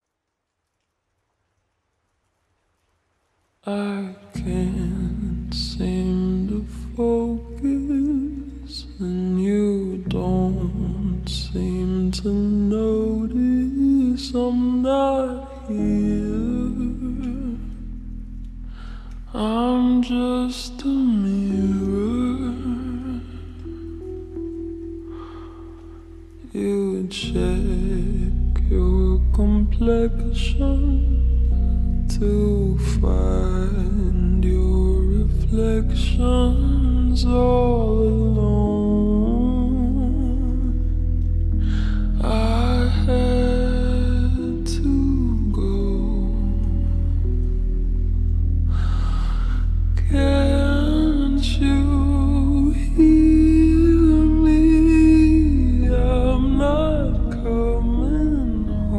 slowed down